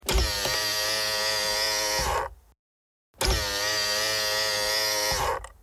Звук моторчика складывания боковых зеркал